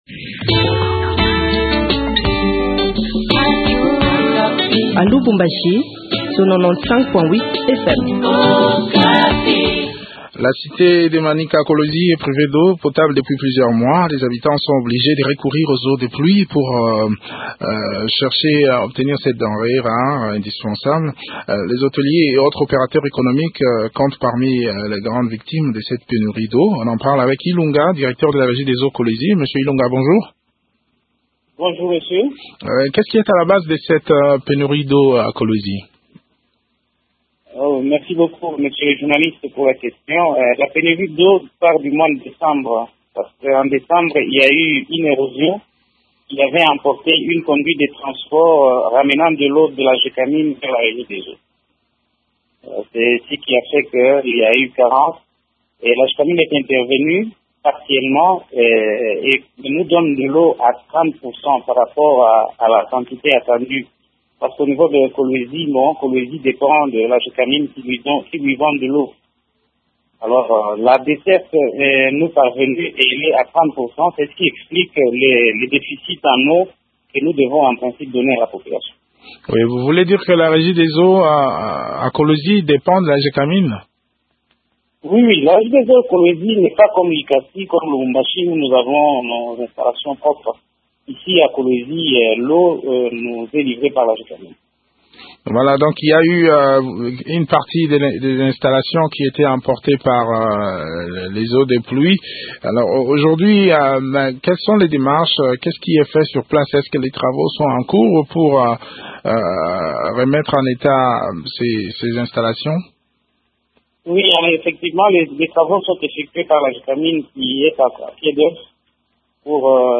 Comment pallier à cette pénurie d’eau à Kolwezi ? Le point du sujet dans cet entretien